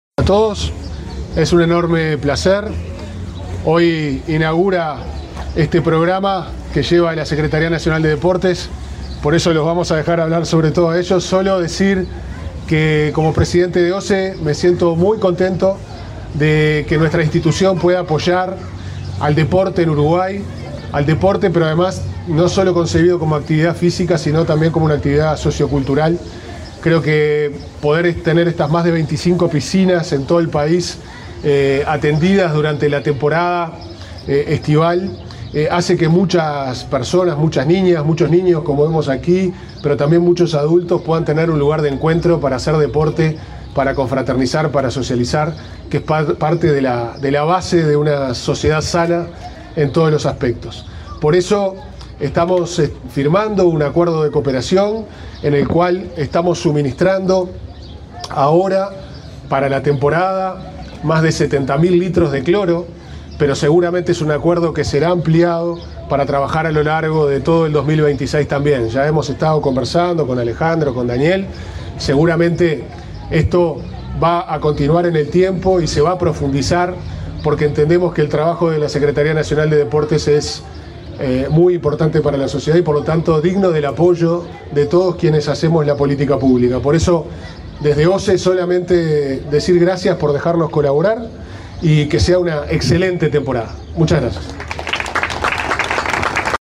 Palabras del presidente de OSE, Pablo Ferreri